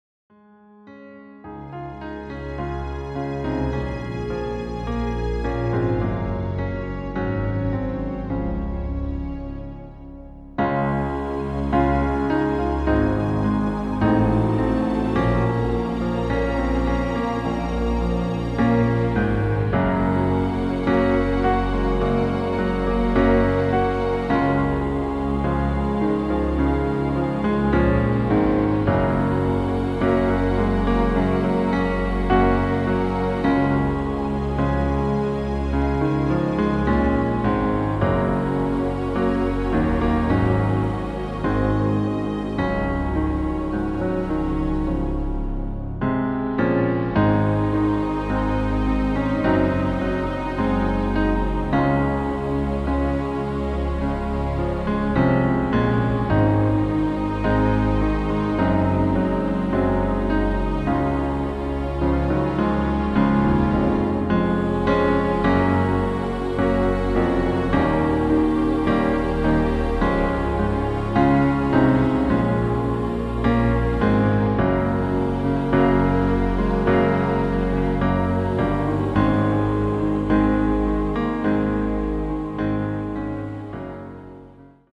• Tonart: C, Cis, D, F, H
• Das Instrumental beinhaltet NICHT die Leadstimme